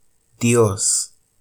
[ˈdjos] 'God', vio [ˈbjo] 's/he saw', etc., the semi-vowel [j] is unrounded; if it were rounded, a sound that does not exist in Spanish, [ɥ], would appear.